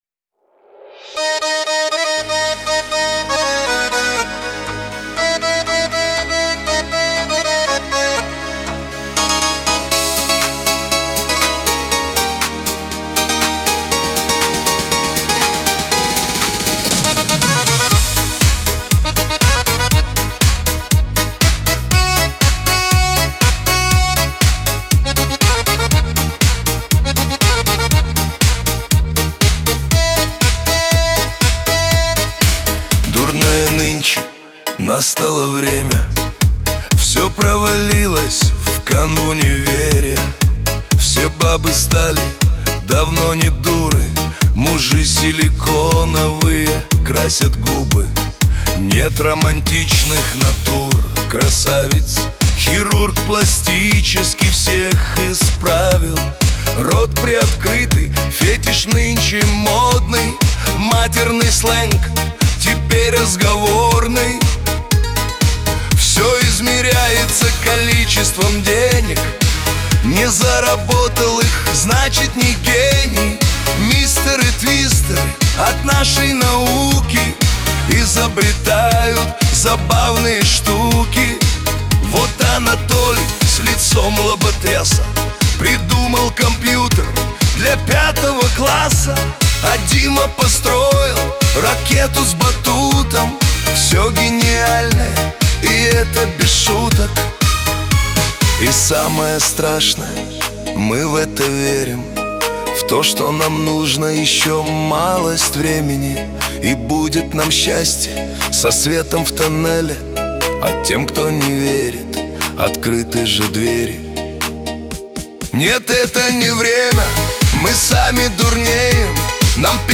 Лирика , грусть
Шансон